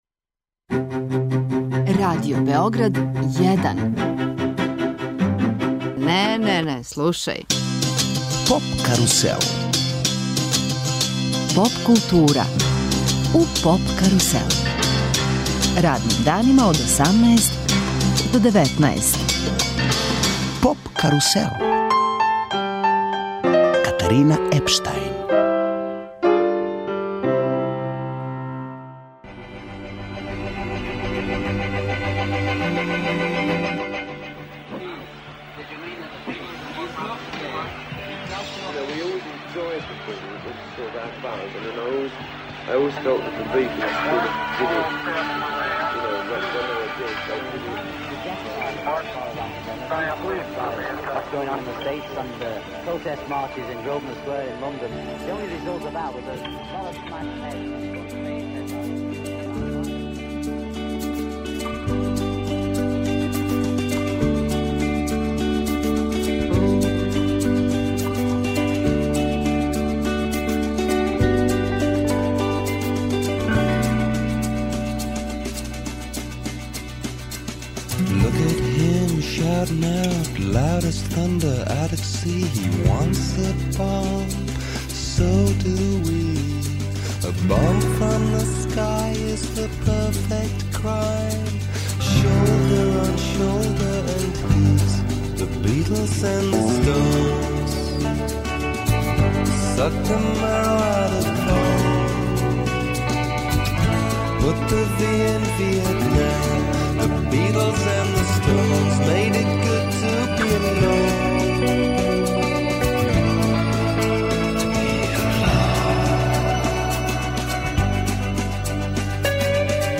Састав Синестезија свираће уживо у студију Радио Београда 1 а причаћемо и о концерту који ће одржати 9. маја у Ган клубу.